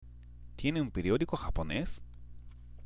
（ティエネ　ウン　ペリオディコ　ハポネス？）